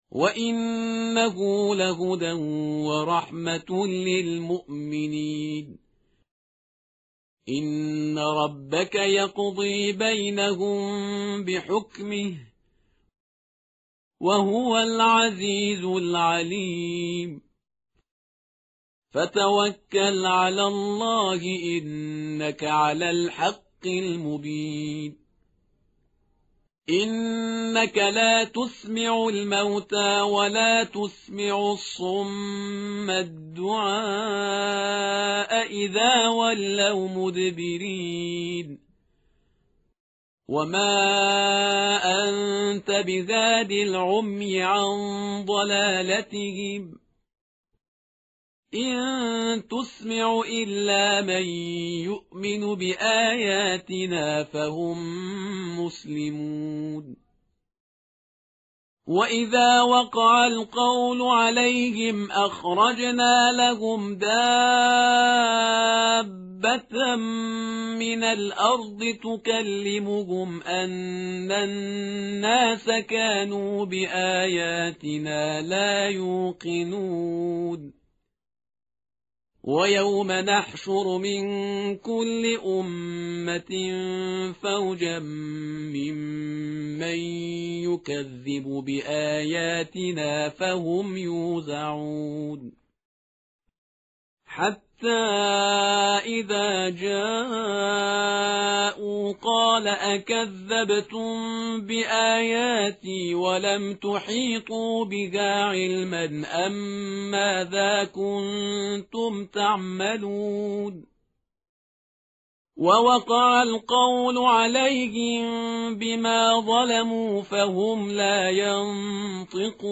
tartil_parhizgar_page_384.mp3